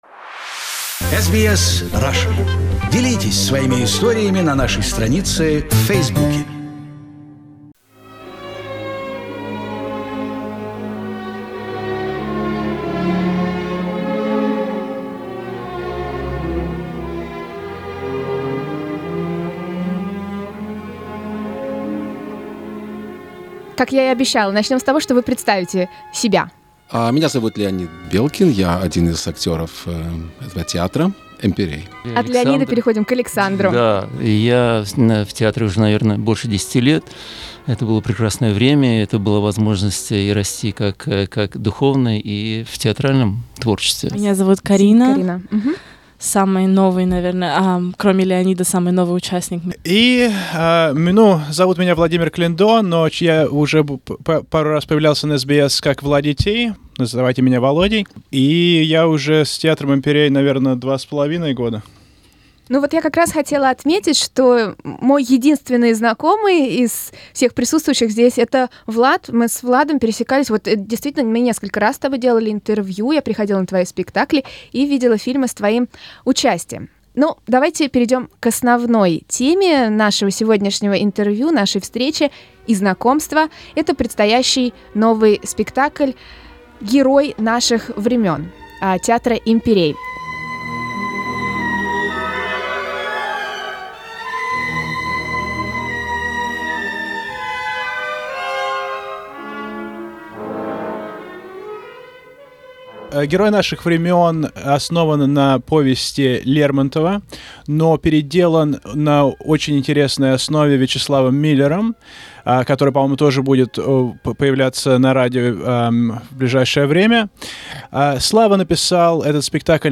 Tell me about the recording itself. The part of the crew of 'Hero of Our Times' visited out studio to share their excitement about the upcoming premiere.